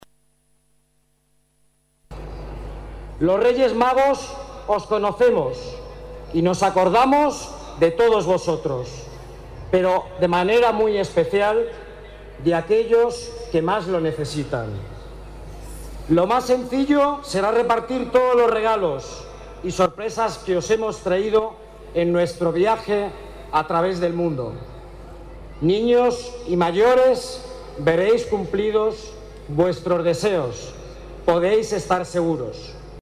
Miles de niños aguardaban esta tarde la llegada de los Reyes Magos que, procedentes del lejano Oriente, hacían su entrada en Madrid por el Paseo de la Castellana.
Nueva ventana:SS.MM. el Rey Melchor: Saludo a los niños